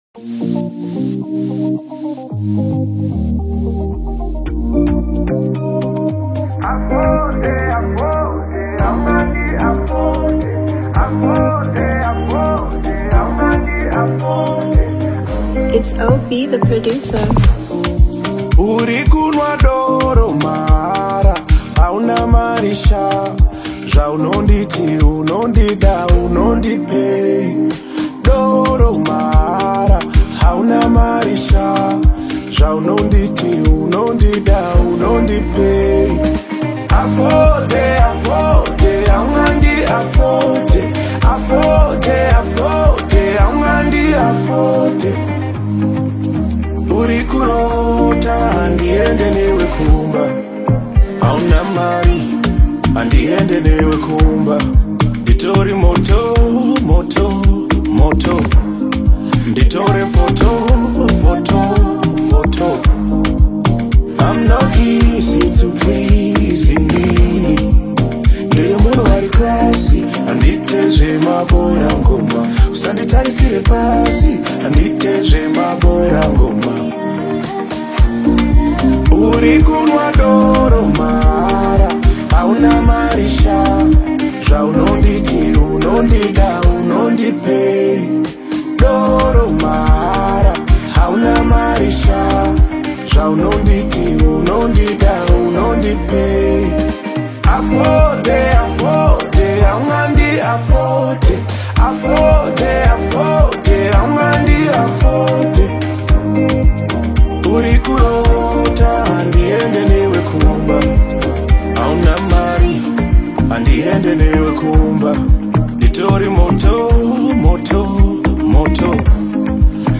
Afro-Pop/Afro-fusion
modern African sound